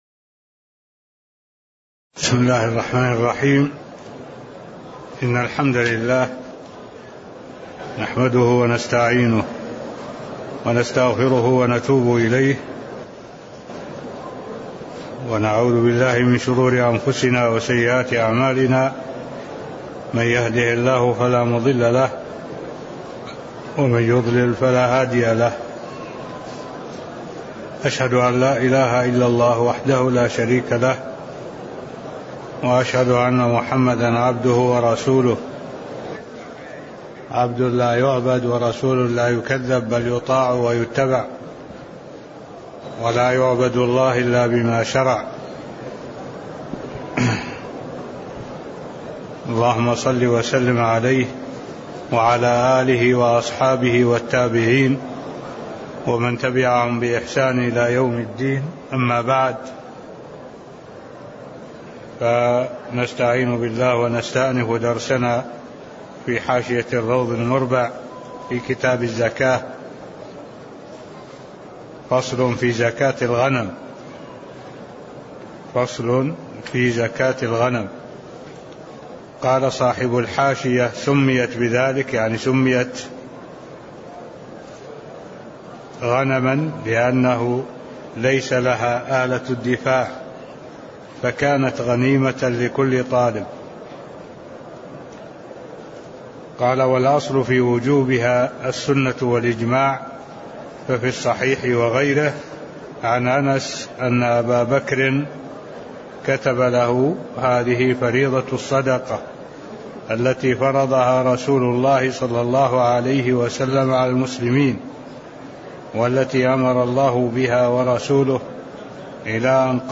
تاريخ النشر ١٣ ربيع الثاني ١٤٢٩ هـ المكان: المسجد النبوي الشيخ: معالي الشيخ الدكتور صالح بن عبد الله العبود معالي الشيخ الدكتور صالح بن عبد الله العبود فصل في زكاة الغنم (009)